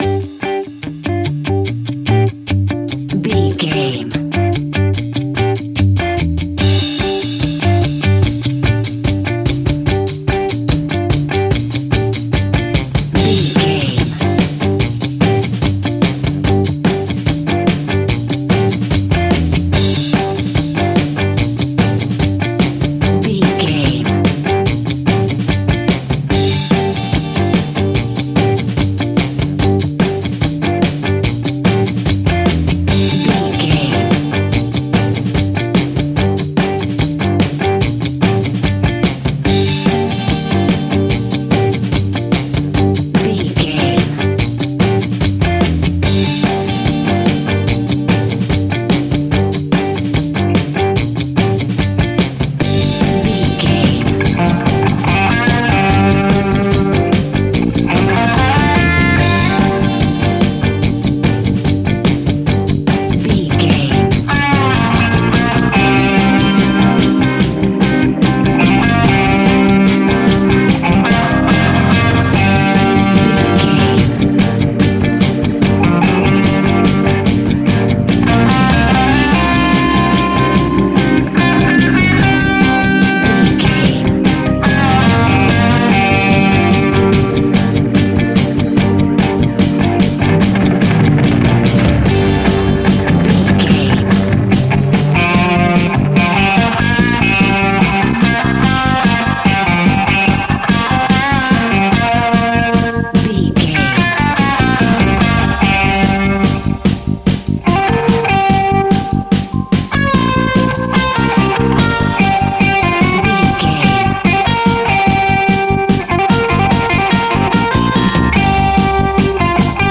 Aeolian/Minor
cool
uplifting
bass guitar
electric guitar
drums
cheerful/happy